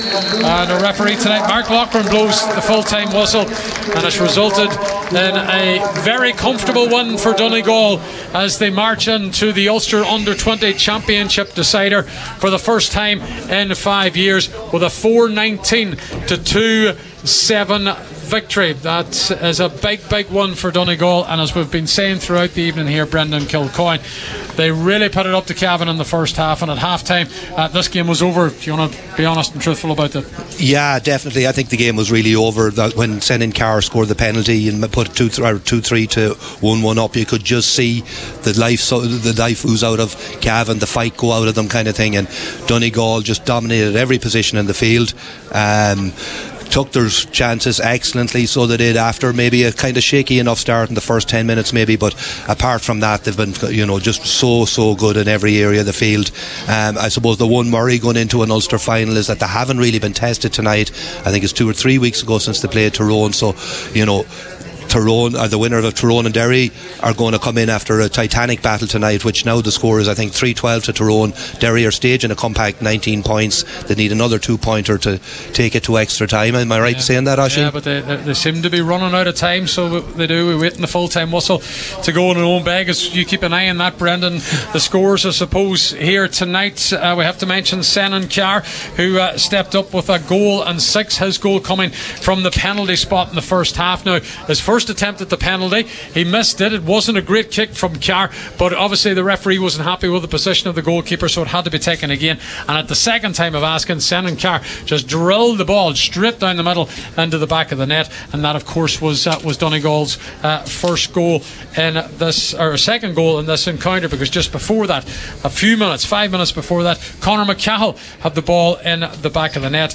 were live at full time for Highland Radio Sport…